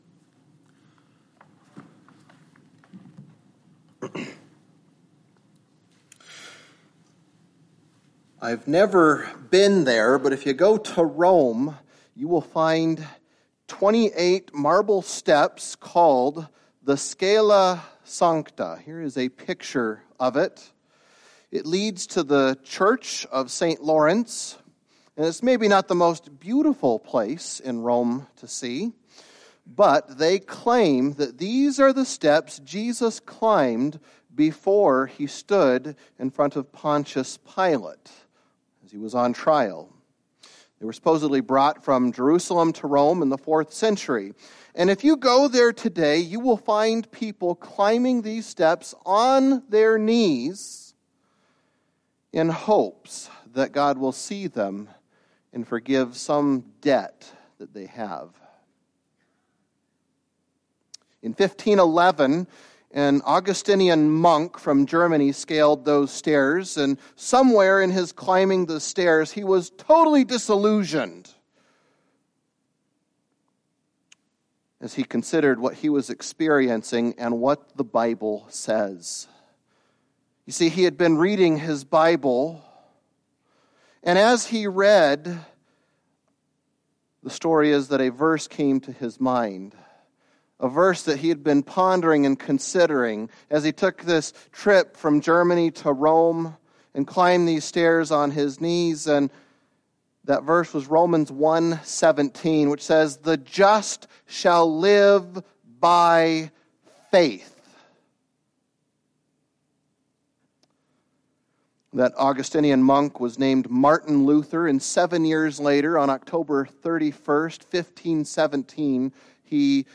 A message from the series "Galatians."